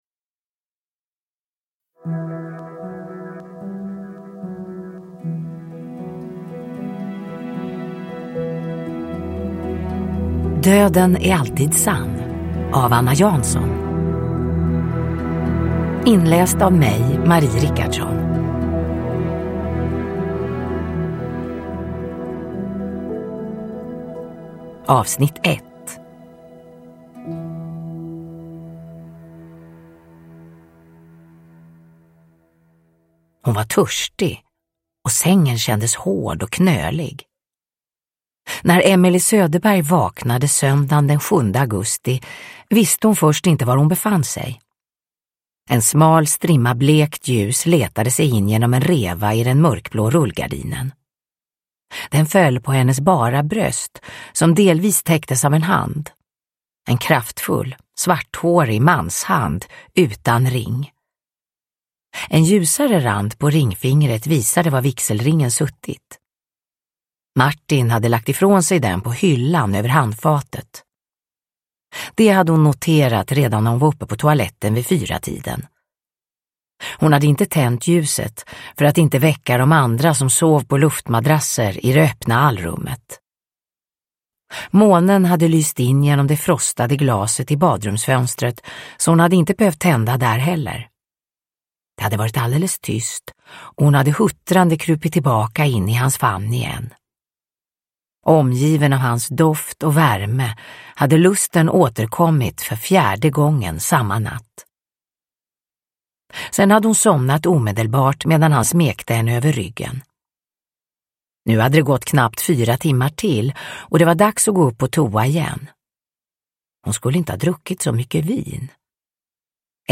Döden är alltid sann - 1 – Ljudbok – Laddas ner
Uppläsare: Marie Richardson